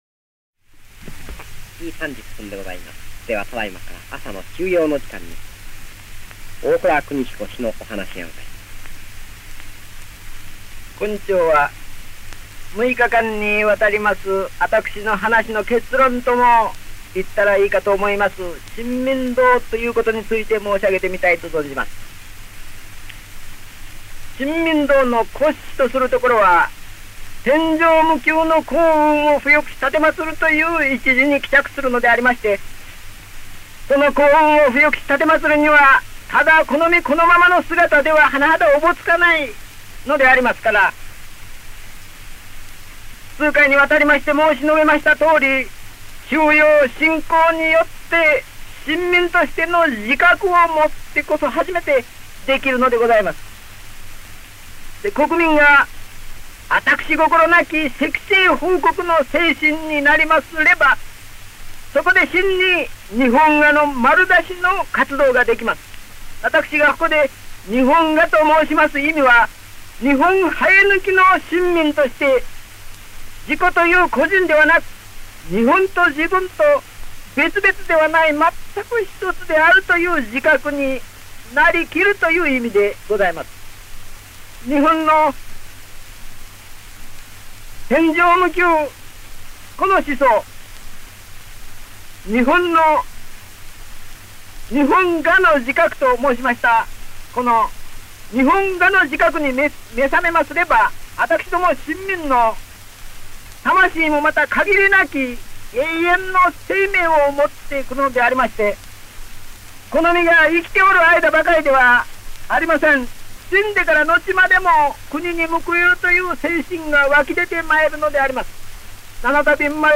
大倉邦彦は、NHK東京放送局JOAKで、昭和12年3月25日から30日までの６日間、朝の修養講座「処世金言」と題する講演を行いました。
本音声は、その第6回目（昭和12年3月31日）のラジオ放送を録音したSP盤レコードをデジタル化したものです。